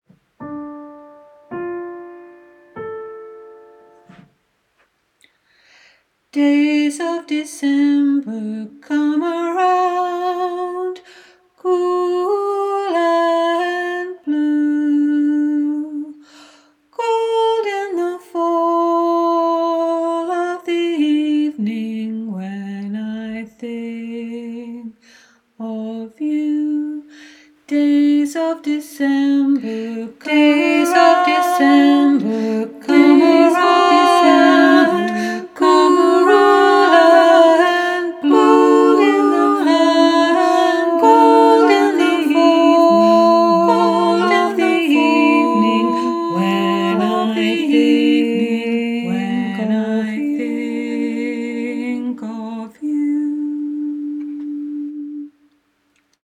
a Winter round with a minor feel